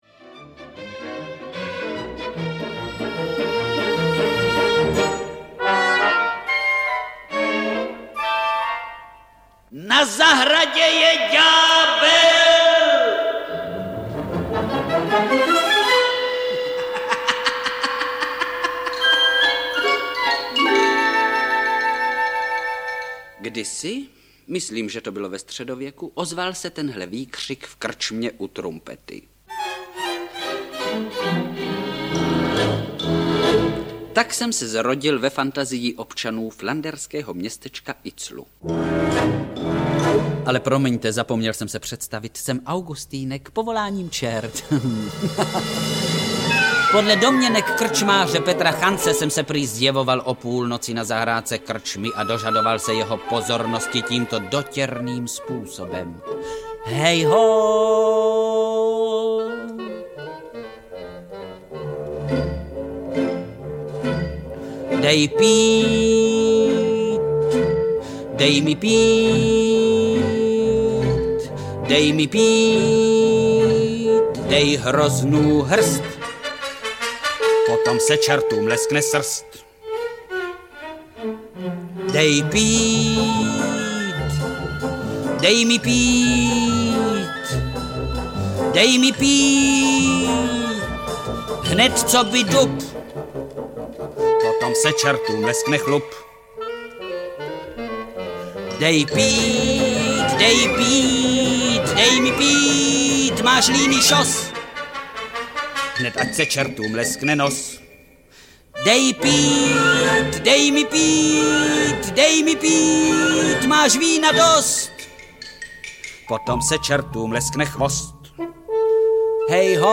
Nezapomenutelný a jedinečný audiokniha
Soubor nahrávek z archivu Supraphonu představuje vynikajícího herce Vlastimila Brodského od jeho jinošských let až do konce života.
Ukázka z knihy
Uslyšíme jej také ve hře Neila Simona Vstupte a v jednoaktových hrách Úředníkovo ráno (N. V. Gogol) a Tragédem proti své vůli (A. P. Čechov).Vlastimil Brodský si získával posluchače jasnou kombinací věcného nepatetického projevu s prvky ironického humoru a zajímavě prosakujícího komična. Zároveň byl jeho herecký projev vždy uvěřitelný, citlivý a pravdivý.